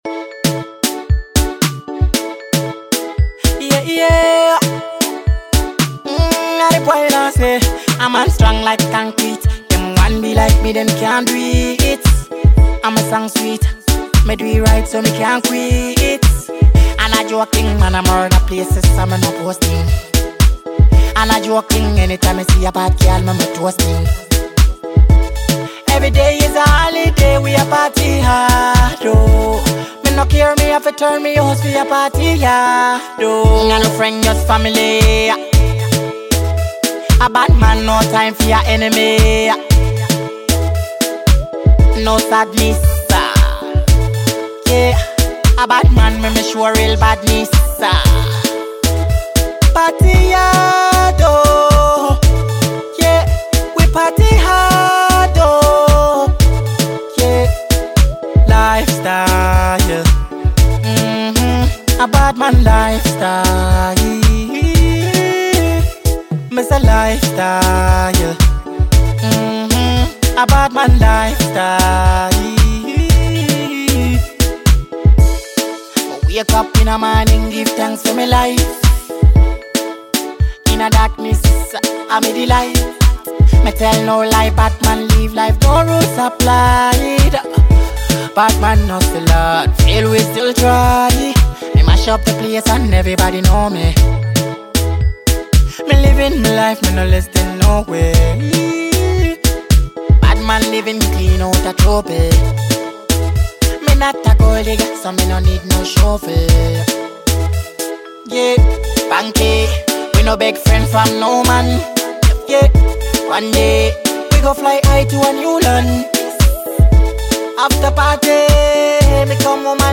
With its catchy rhythm and standout lyrics
Most of his songs are Afrobeat and Dancehall.